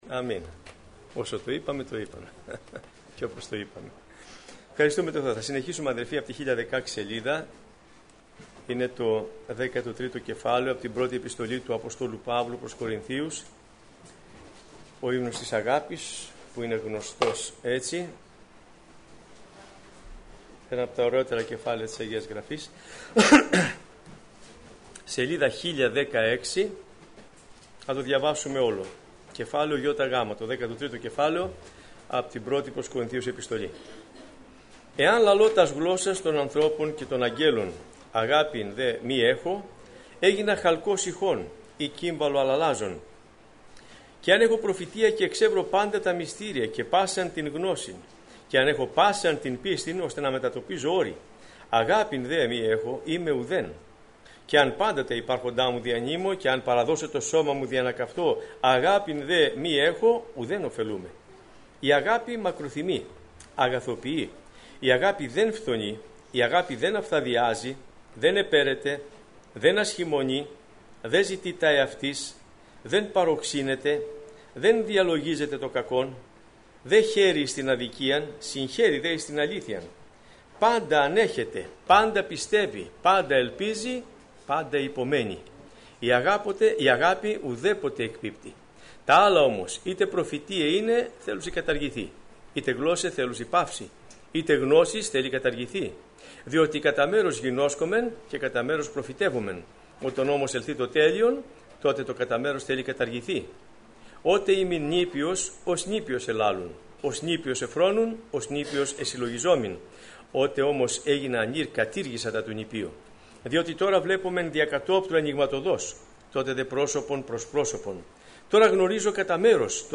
Σειρά: Μαθήματα